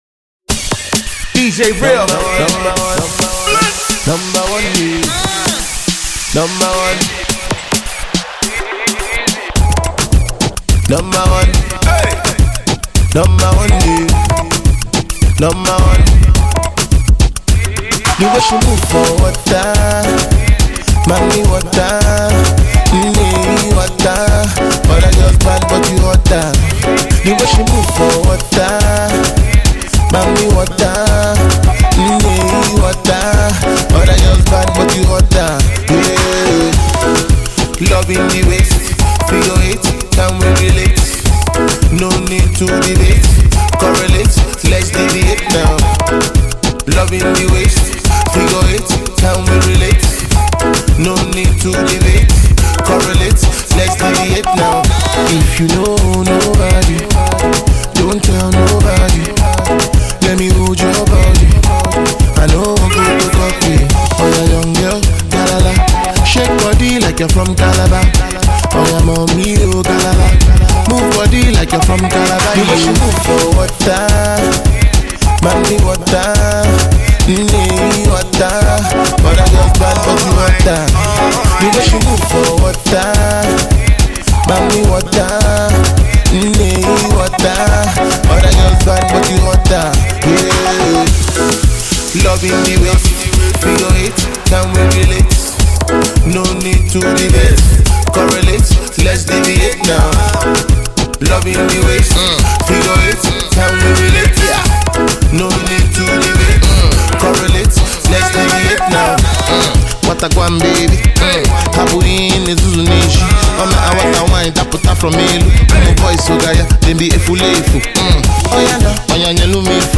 Afro-DanceHall